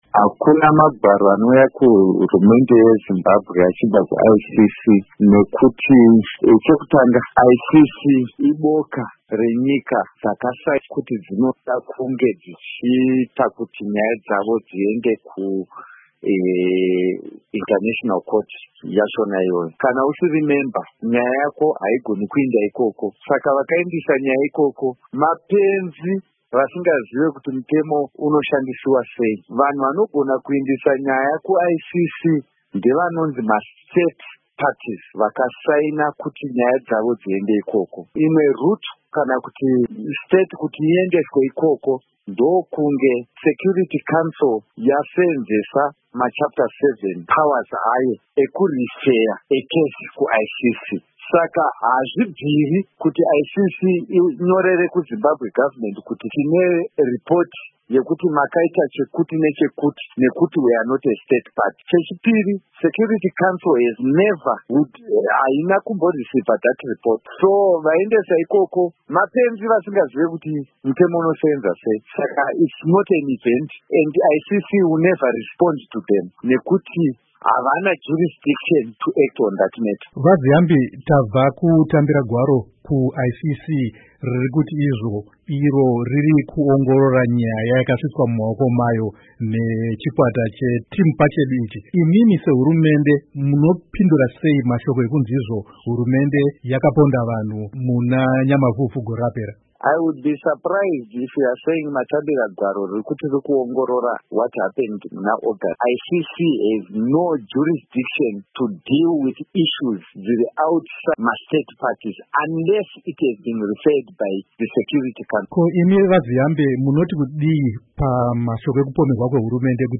Embed share Hurukuro naZiyambi Ziyambi by VOA Embed share The code has been copied to your clipboard.